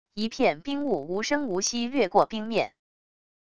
一片冰雾无声无息掠过冰面wav音频